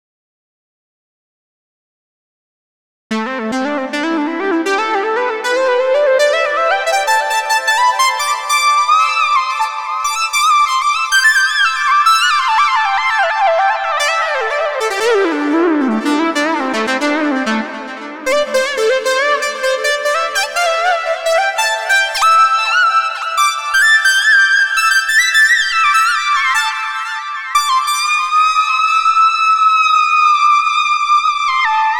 Groovy Lead Synth 60 bpm .wav